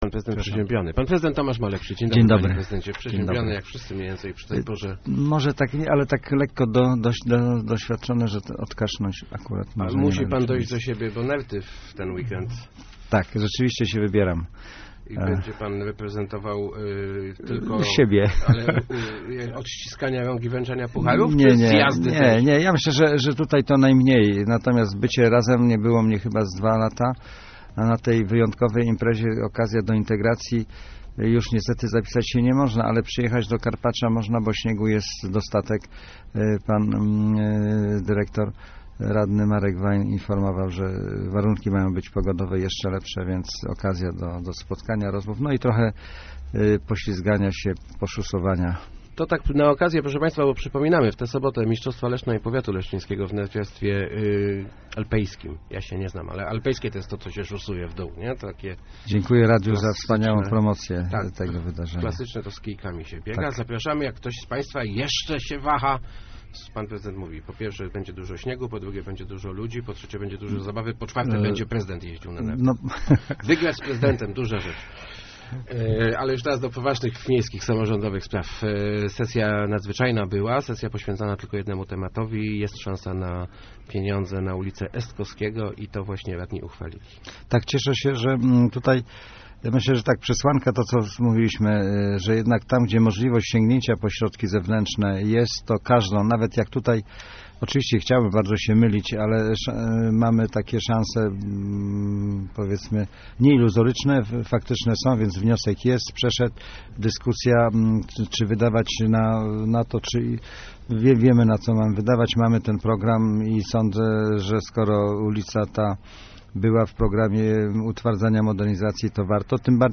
Gościem Kwadransa był prezydent Leszna Tomasz Malepszy. ...